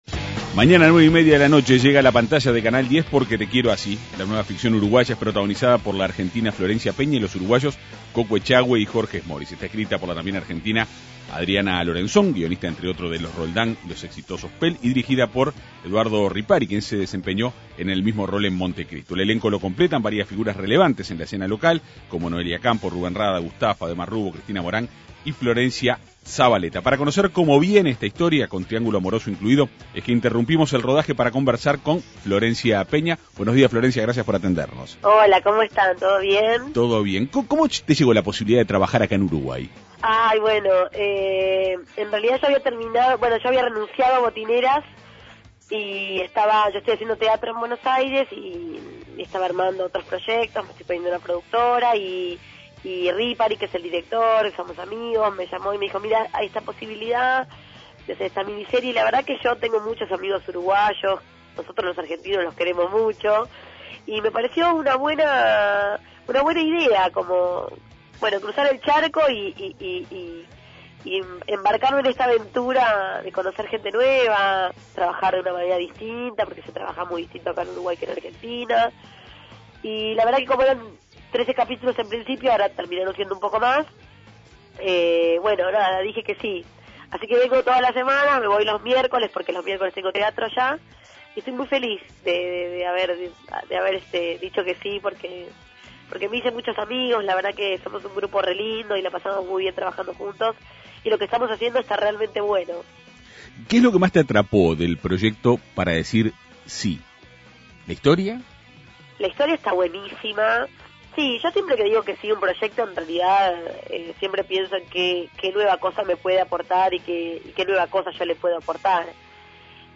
Para conocer esta historia la Segunda Mañana de En Perspectiva entrevistó a la actriz porteña.